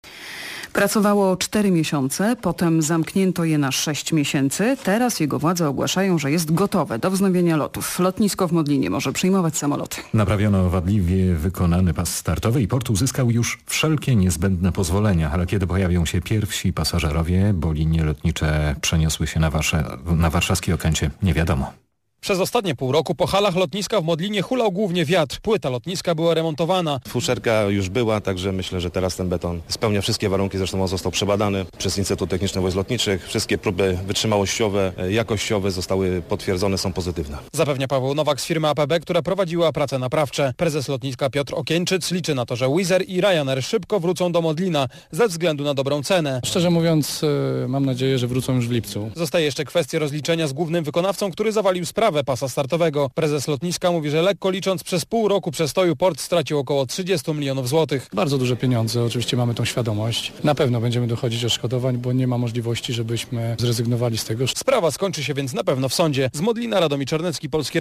Relacja Radiowej Trójki na temat remontu lotniska Modlin , nadzorowanego przez A.P.B. Analiza, Projekt, Budowa